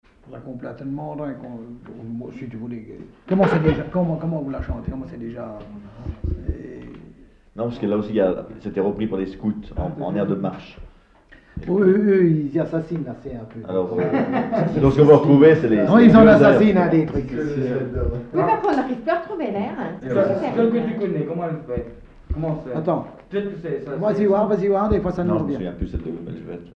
Localisation Verchaix
Catégorie Témoignage